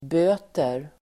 Uttal: [b'ö:ter]